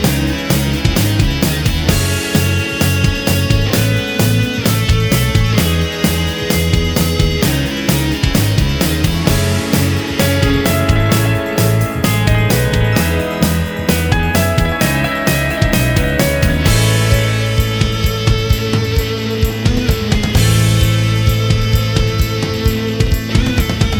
Minus Lead Guitars Indie / Alternative 4:07 Buy £1.50